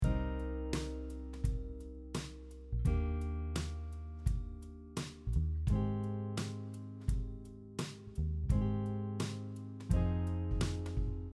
Here is one last example of using drop 2 chords over a series of different chords.
drop 2 chords over a series of different chords application example